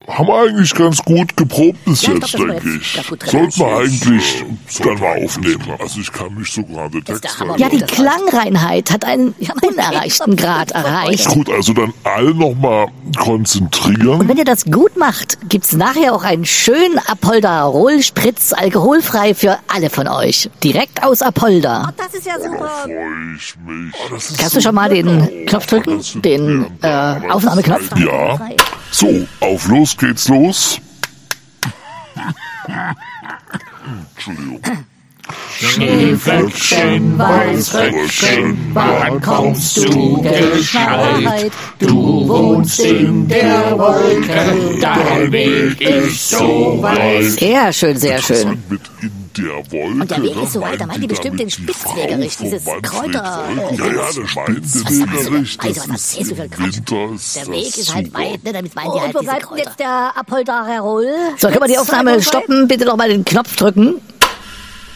Ich nehme ja immer alles in einem Rutsch auf, kürze dann alles auf exakt 59:30min zurecht, mach die Blenden sauber, lege noch ein extra Album unter die Sprache und DANN ist es fertig.
Also alle Musik und Jingles noch mal neu aufgenommen und dann zwischen die Sprechpausen gepfriemelt... das war bisschen sehr überflüssig...
Und jetzt kommt's – in guter alter Tradition bekommt Ihr Fedinaut:innen das Hörspielintro zur Sendung schon jetzt – exklusiv im Fediverse!